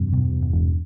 标签： 电子吉他 电子弓 踏板盾 开放硬件
声道立体声